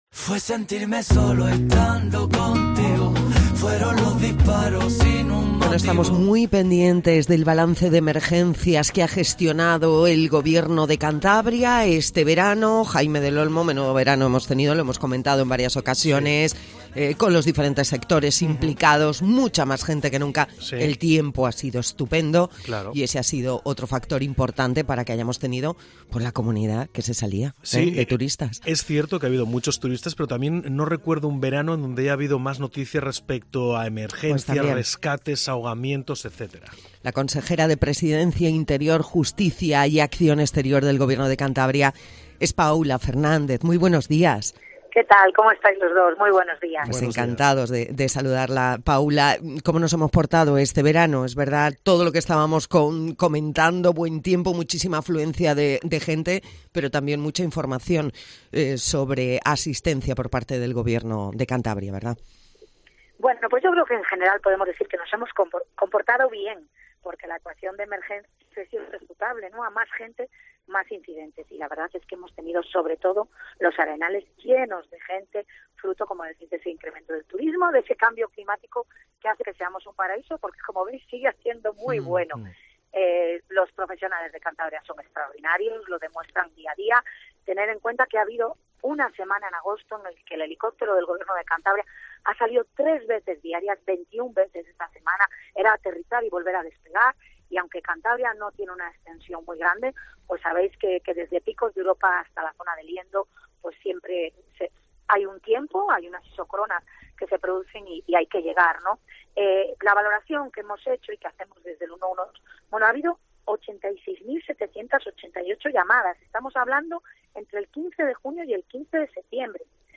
Paula Fernández en COPE Cantabria hace balance del 112